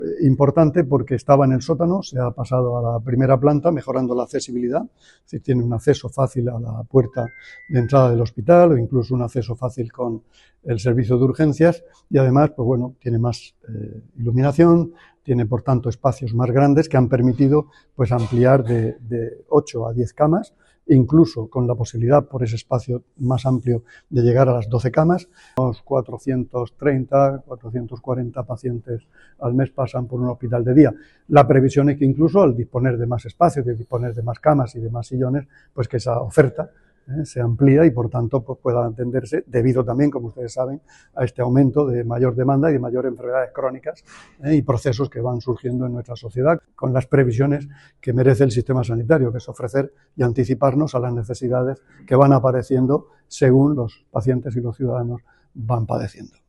Declaraciones del consejero de Salud sobre la ampliación del Hospital de Día del Rosell.[mp3]